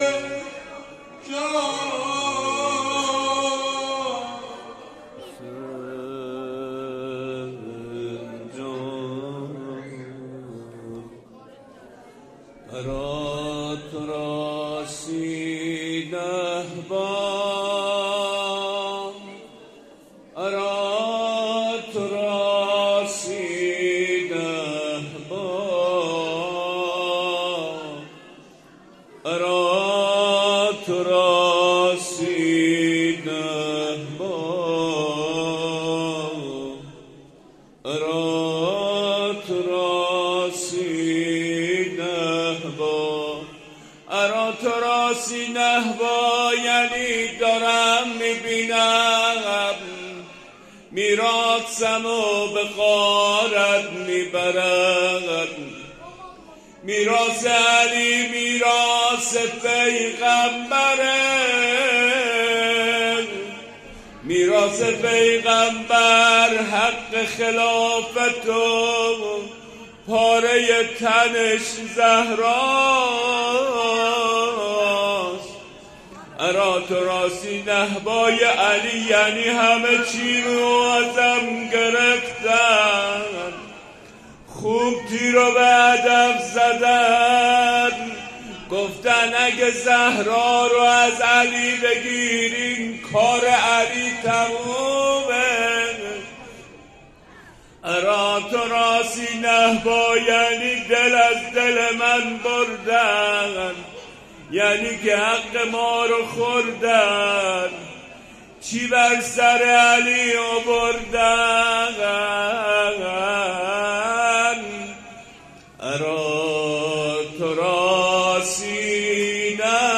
اری تراثی نهبا روضه